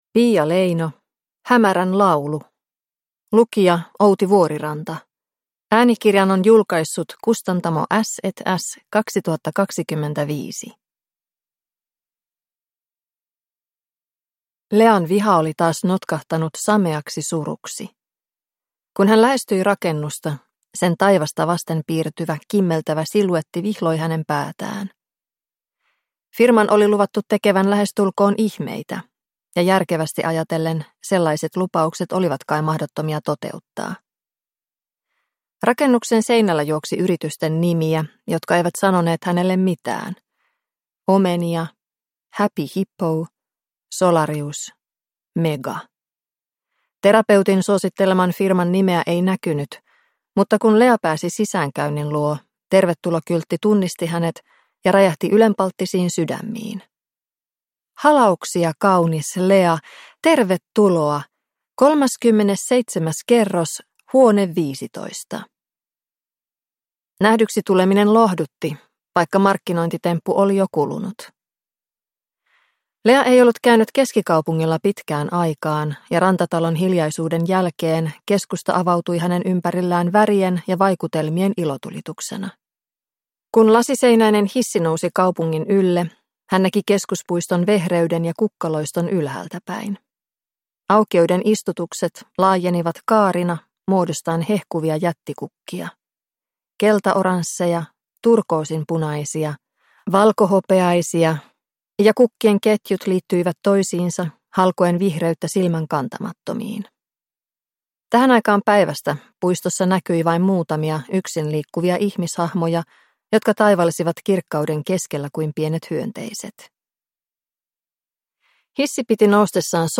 Hämärän laulu (ljudbok) av Piia Leino